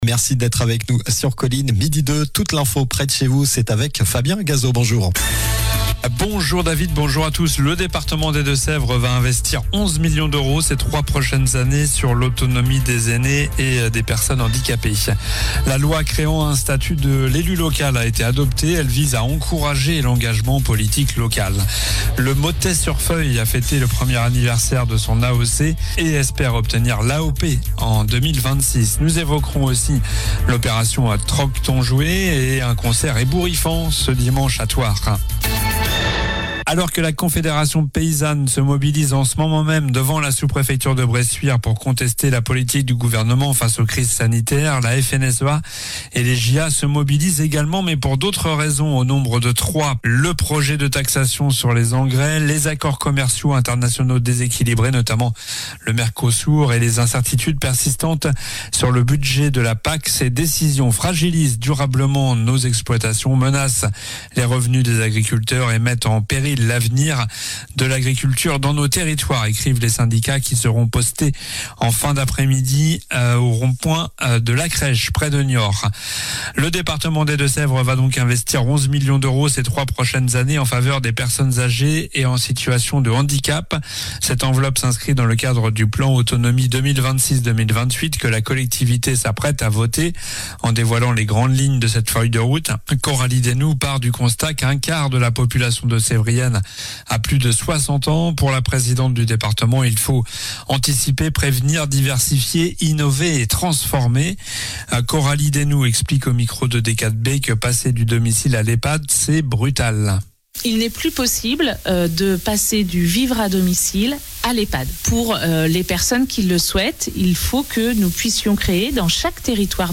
Journal du mercredi 10 décembre (midi)